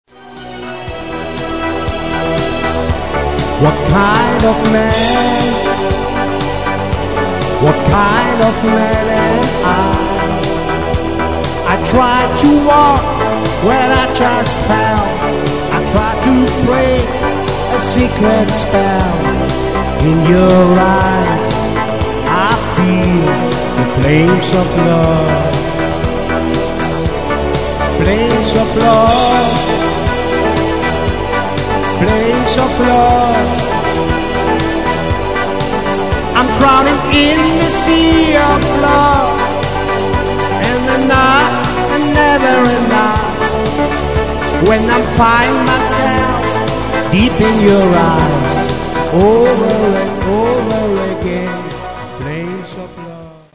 Das Pop/Rock-Musik Programm